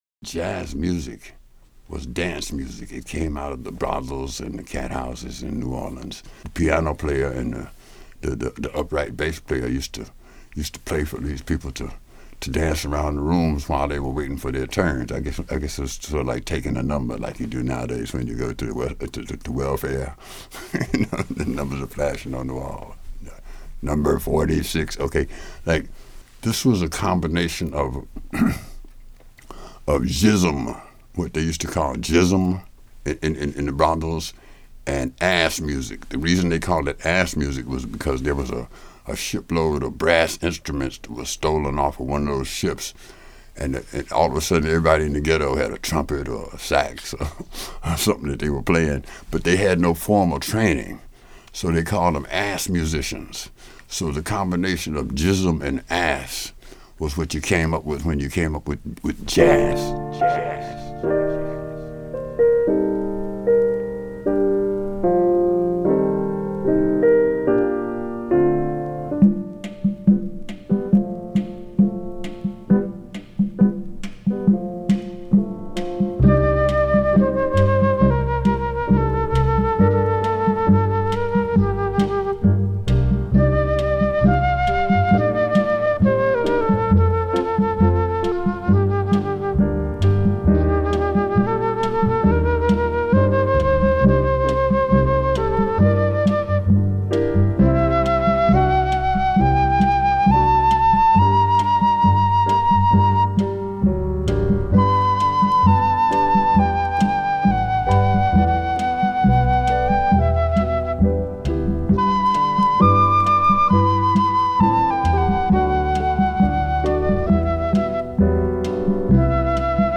煙たいクラブの奥、針を落とす瞬間の静寂。 スピーカーから滲み出る低音―― 黒く、深く、ソウルを通過したジャズ。
滑らかに繋がりながらも芯はぶれない。 甘さの奥に潜む影。
〈試聴〉※前半10分ダイジェスト https